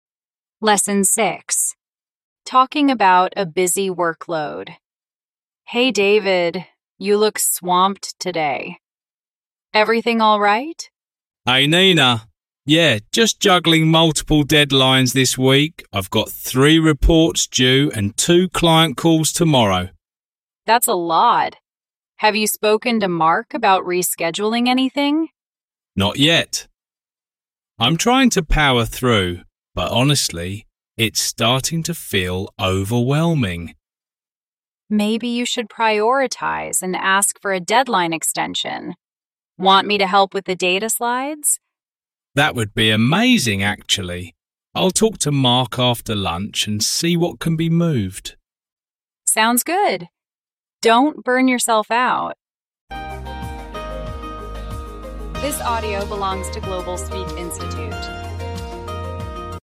• Hình thức: Sách + Audio luyện phản xạ
Giọng chậm